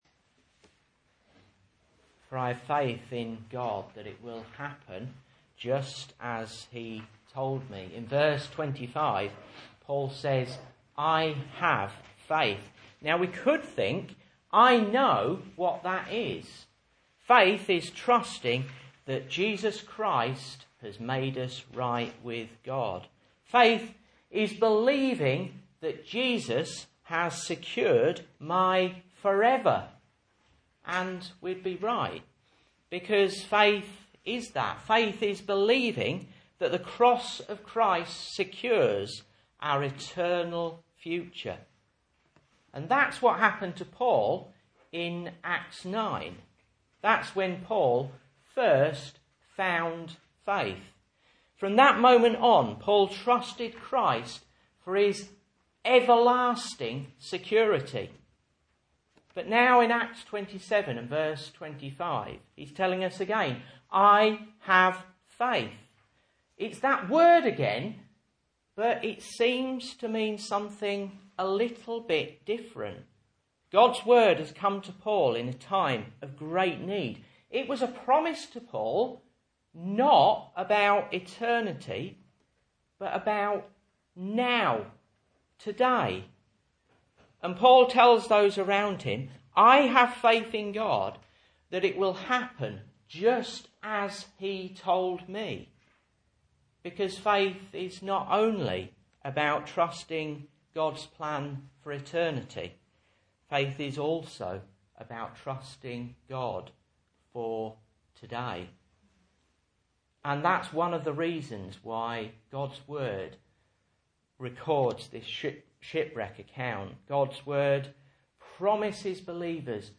Message Scripture: Acts 27:9-28:1 | Listen